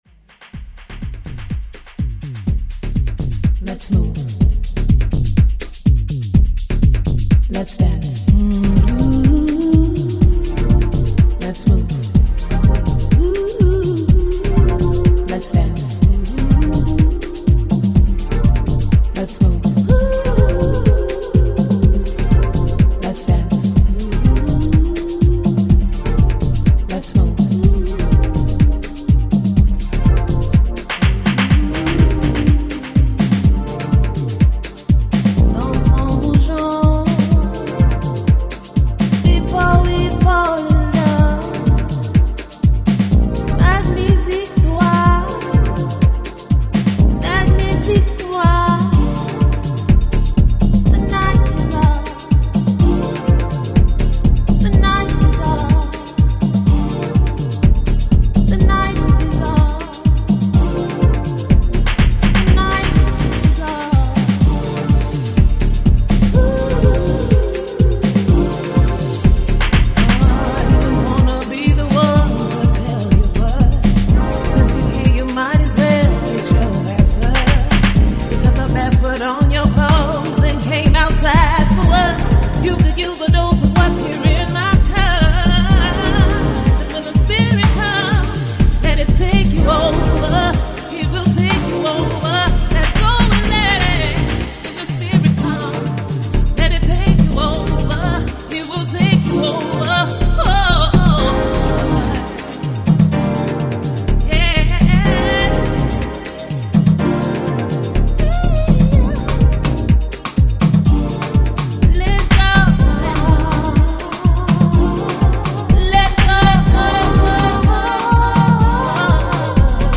Vocal Remix